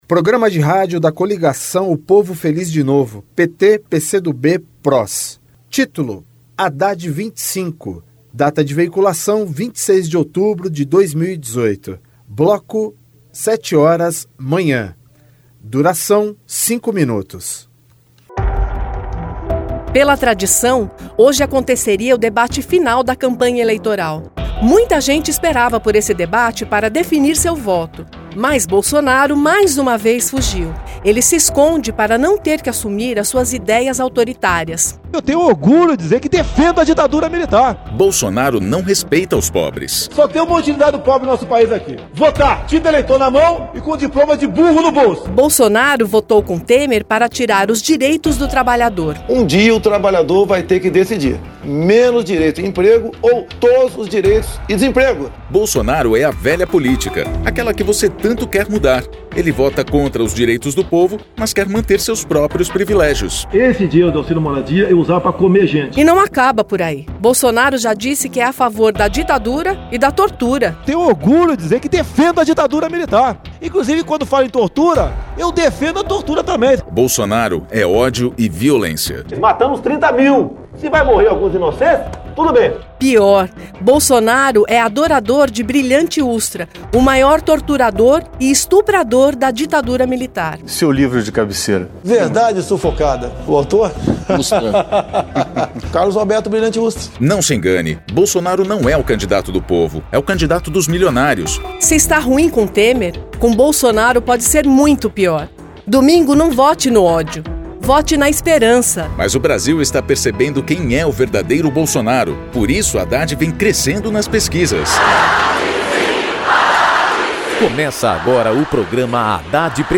TítuloPrograma de rádio da campanha de 2018 (edição 55)
Gênero documentaldocumento sonoro